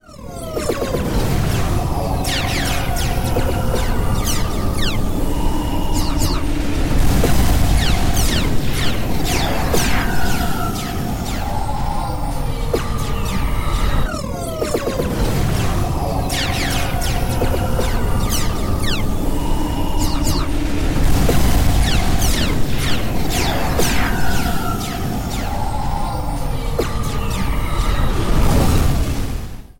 Звук Войны в космосе